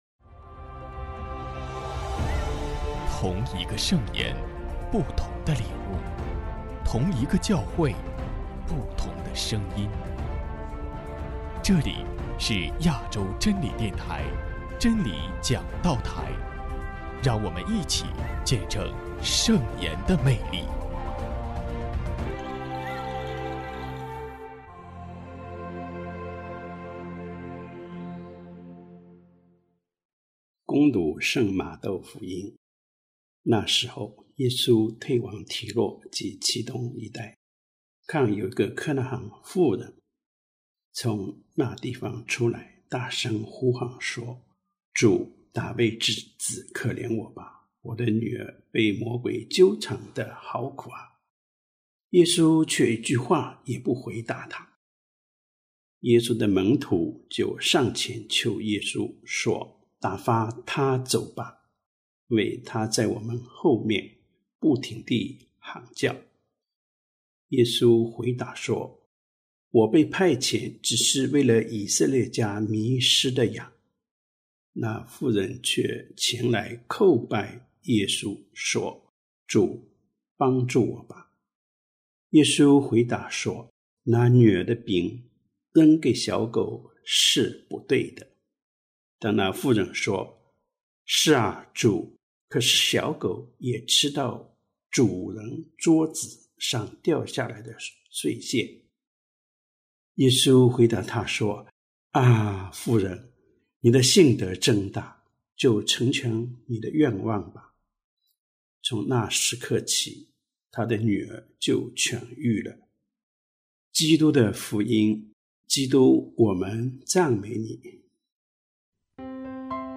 【真理讲道台】| 救恩的普世性——甲年常年期第二十主日